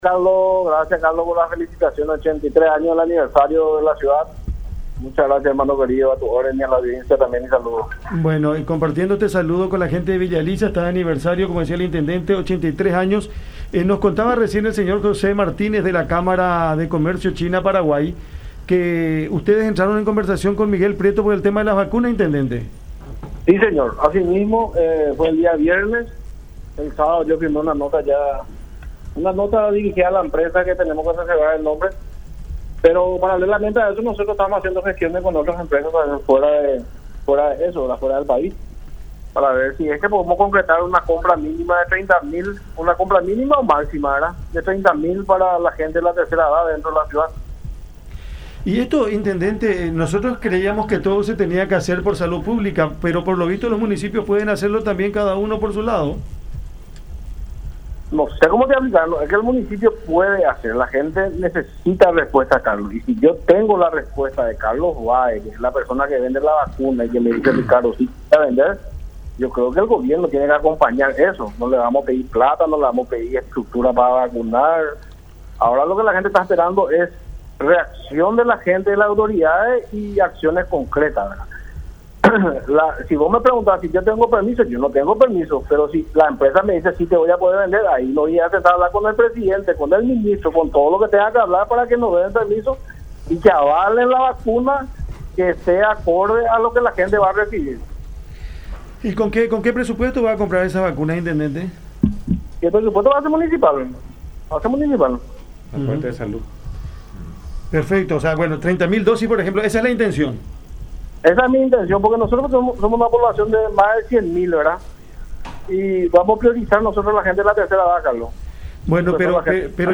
Ricardo Estigarribia, intendente de Villa Elisa.
“El día viernes comenzamos conversaciones y el sábado yo ya firme una nota de intención para la empresa que nos puede traer, por confidencialidad tengo que reservarme, y paralelamente estamos haciendo gestiones para una compra mínima de 30.000 vacunas para la gente de tercera edad”, afirmó Estigarribia en conversación con La Unión.